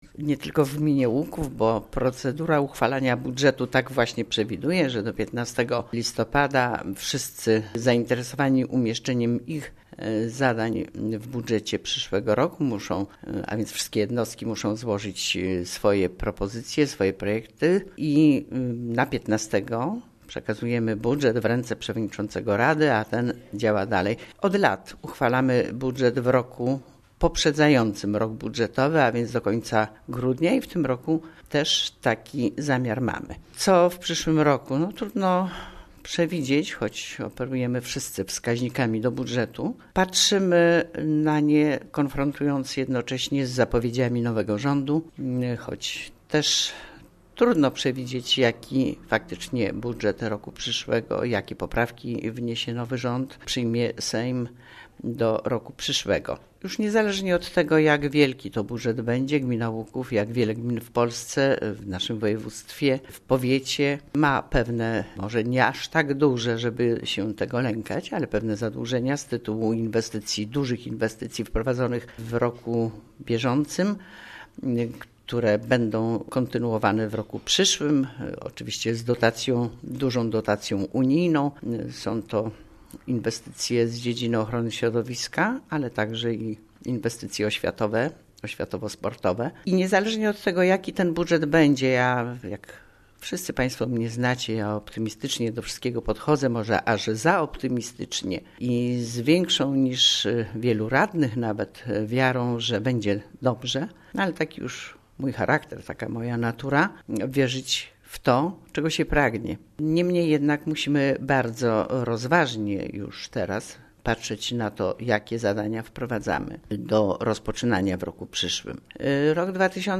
W Gminie �uk�w trwaj� ostatnie przygotowania przysz�orocznego bud�etu. O tym jaki on bedzie informuje: W�jt Gminy �uk�w Kazimiera Go�awska